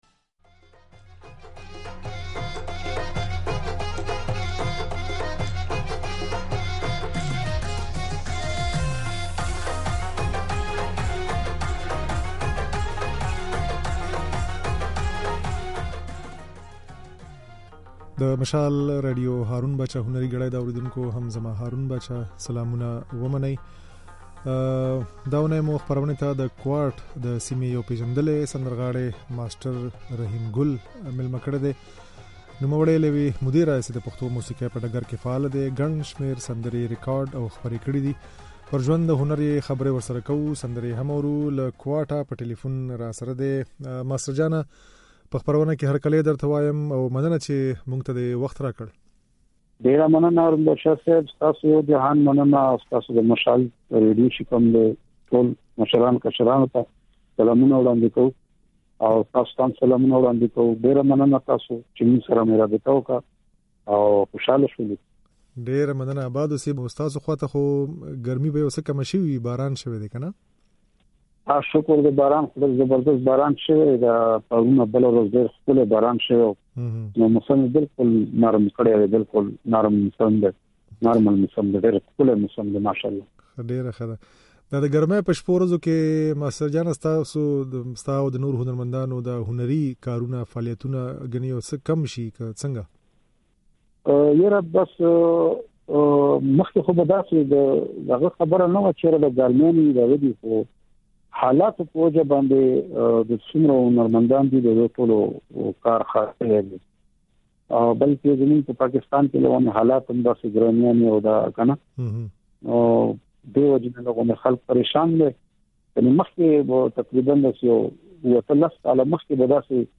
د نوموړي خبرې او ځينې سندرې يې د غږ په ځای کې اورېدای شئ.